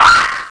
bird2.mp3